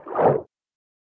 Fish_Attack4.ogg